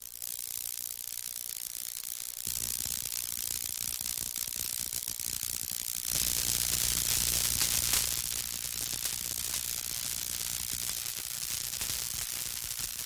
Тайные звуки светящихся насекомых в ночи (запись чистым микрофоном)